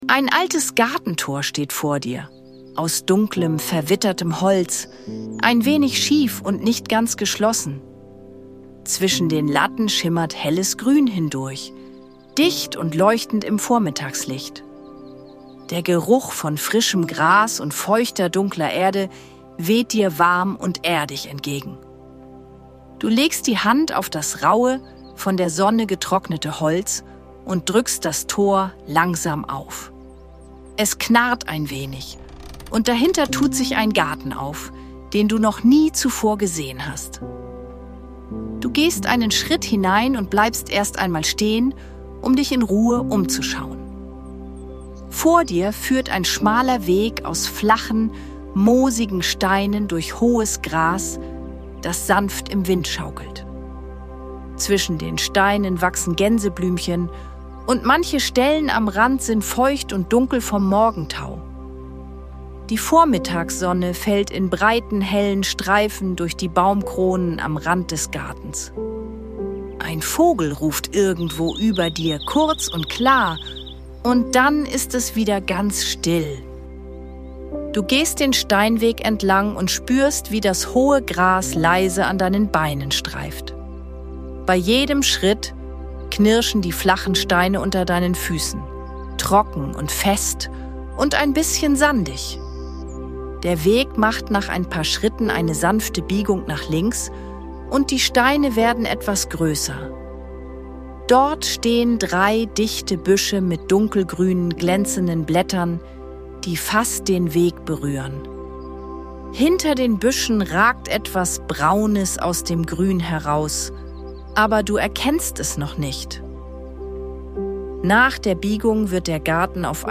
Sanfte Fantasiereisen mit leiser Hintergrundmusik – zum Malen und kreativen Entspannen